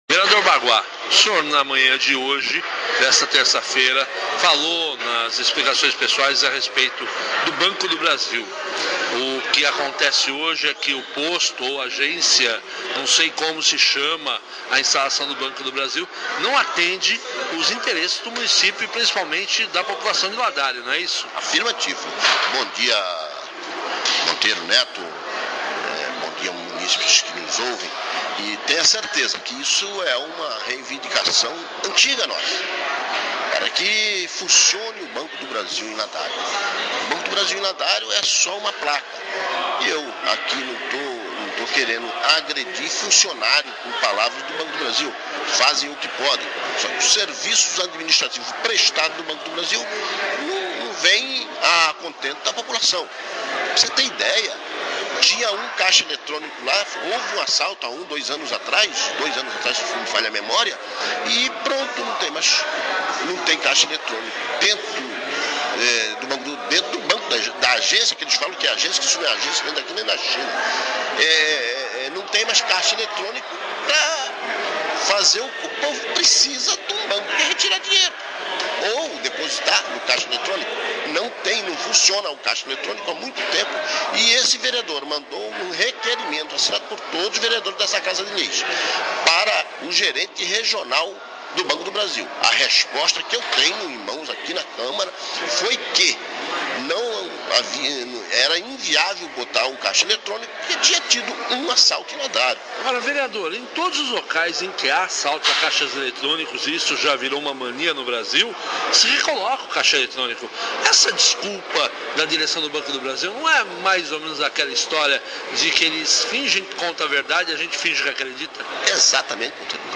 Entrevista com o vereador Baguá
ENTREVISTA NA ÍNTEGRA – PARTE 01